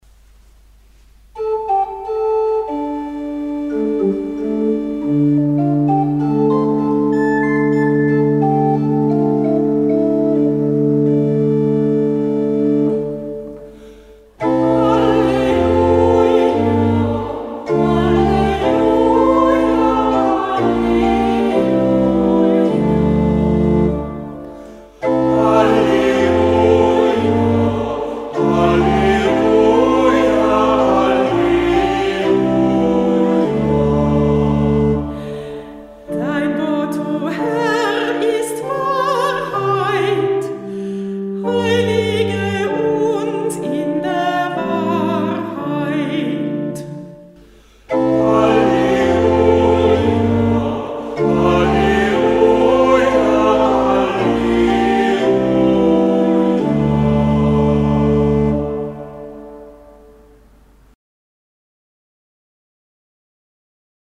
Ruf vor dem Evangelium - September 2024
Kantorin der Verse